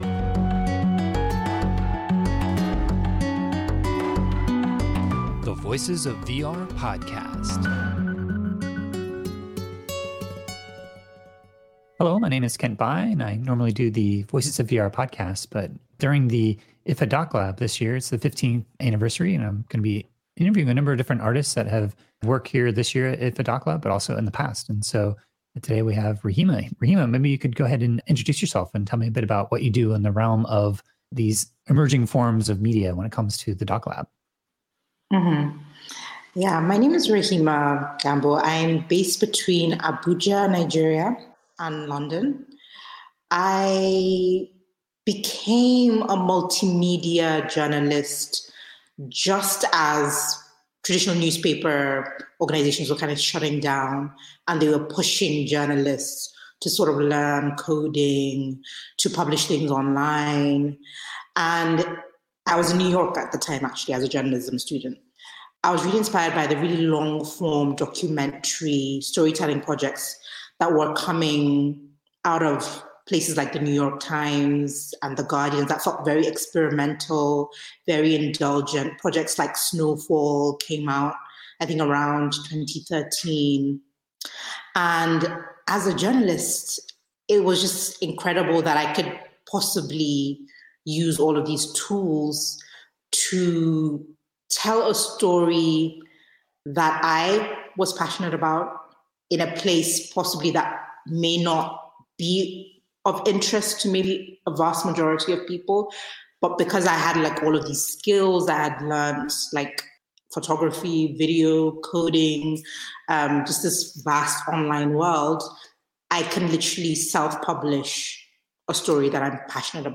This was recorded on Monday, November 22, 2021 as a part of a collaboration with IDFA’s DocLab to celebrate their 15th year anniversary.